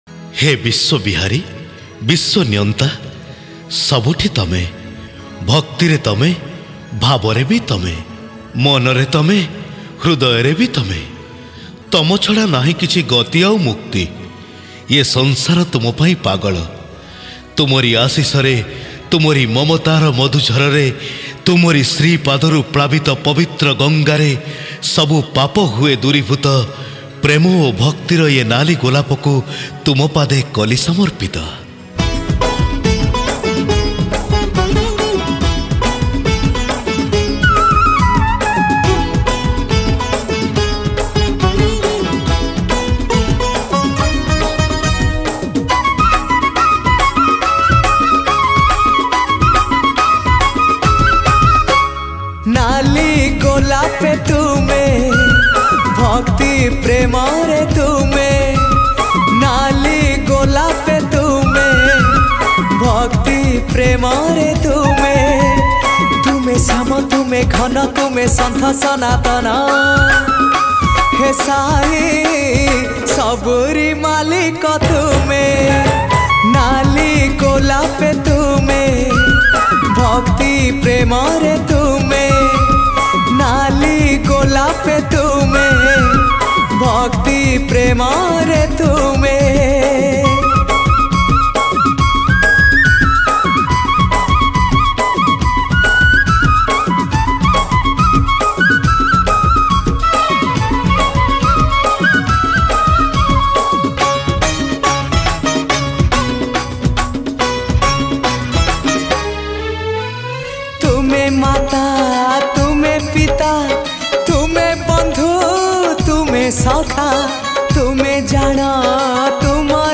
Odia Bhajan Songs